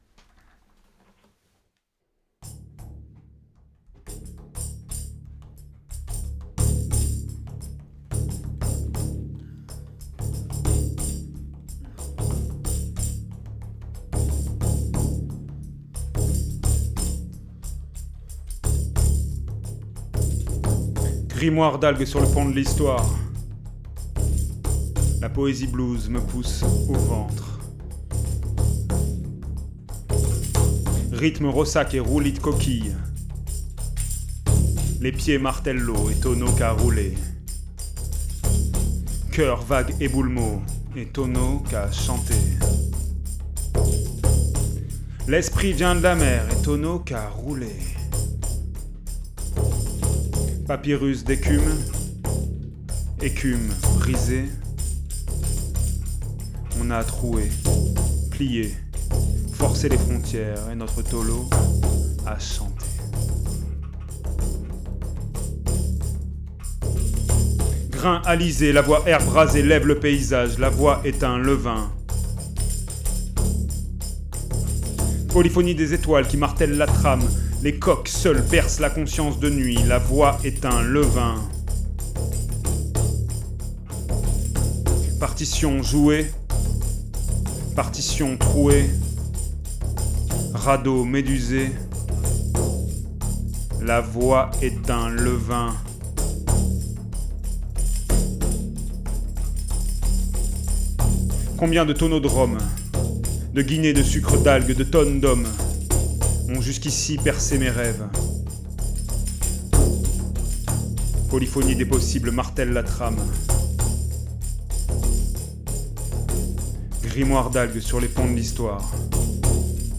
Contrebasse, guitare, percussions
Clarinette, saxophone, percussions